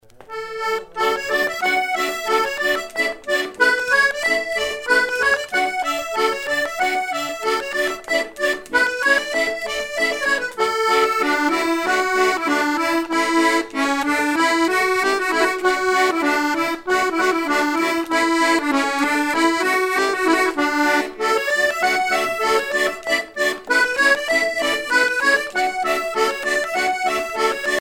Région ou province Anjou
danse : polka